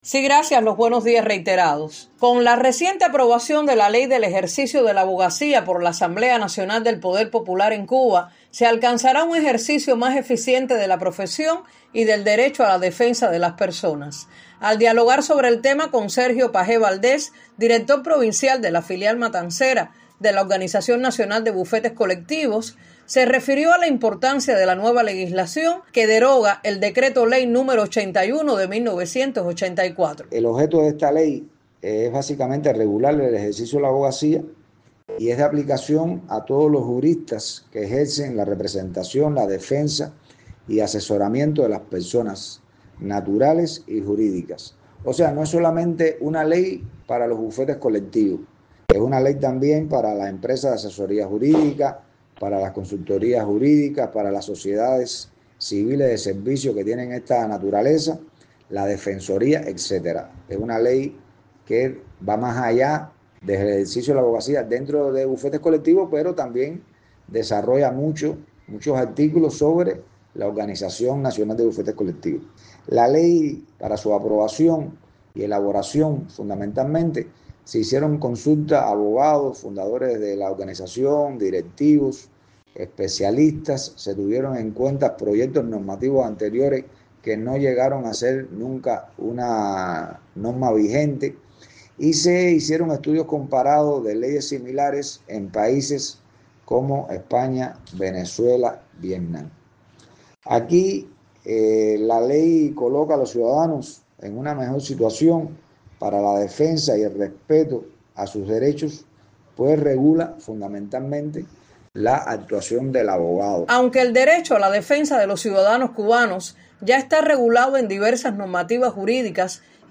en la entrevista que concede a Radio 26